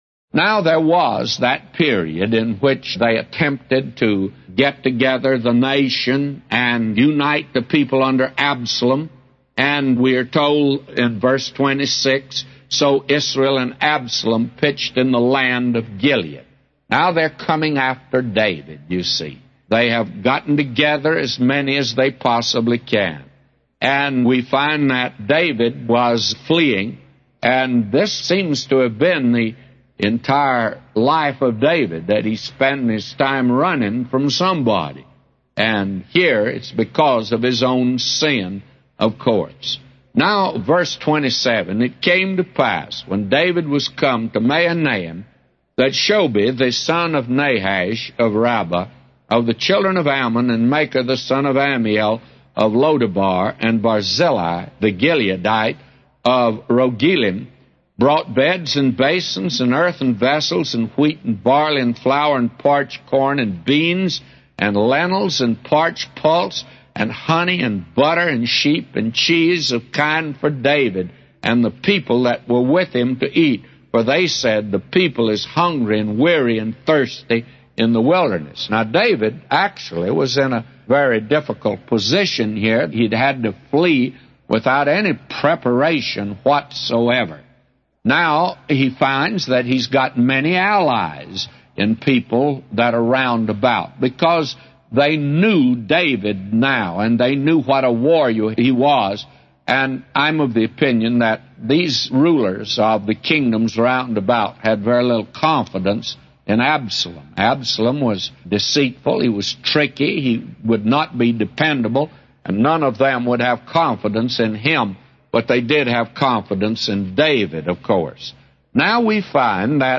A Commentary By J Vernon MCgee For 2 Samuel 17:22-999